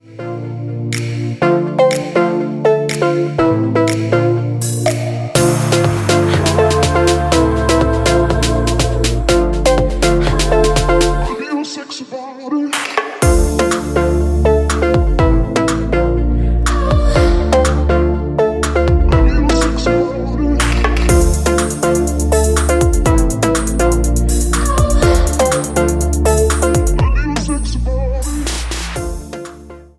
With Backing Vocals